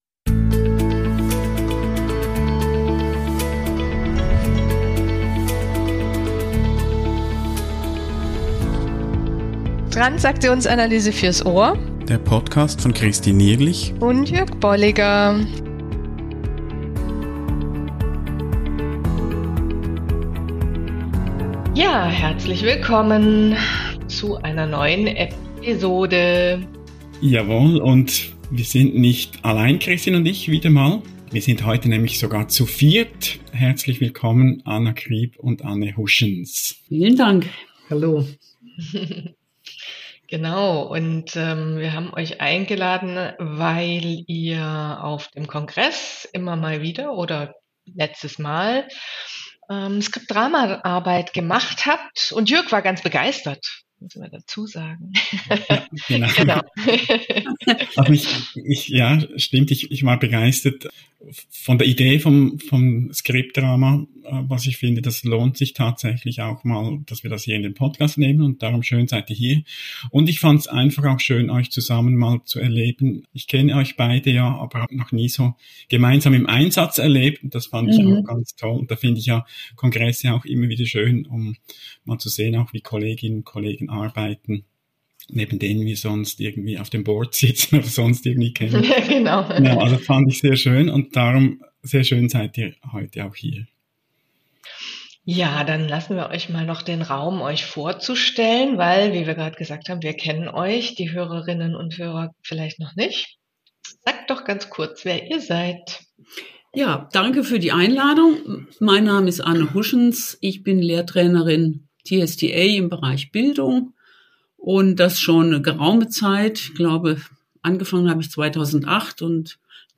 Gespräche über Modelle und Konzepte der Transaktionsanalyse und deren Anwendung